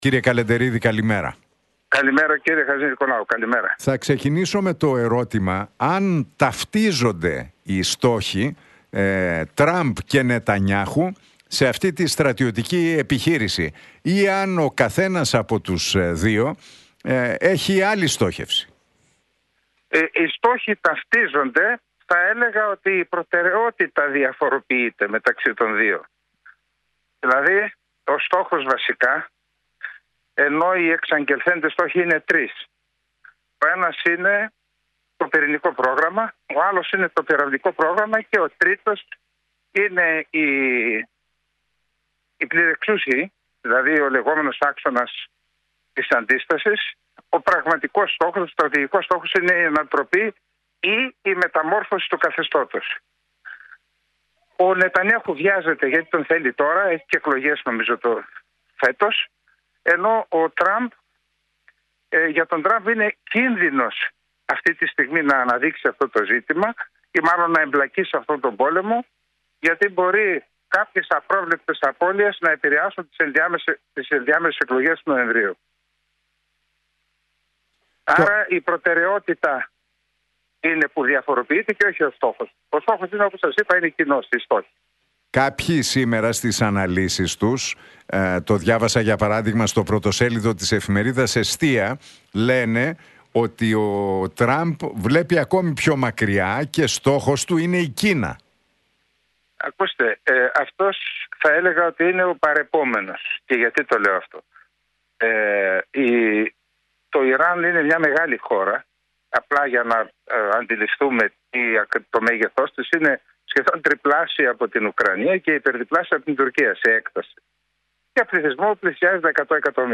Για τη σύρραξη στη Μέση Ανατολή και τους στόχους Τραμπ και Νετανιάχου με την επίθεση στο Ιράν μίλησε ο Γεωπολιτικός Αναλυτής, συγγραφέας και αρθρογράφος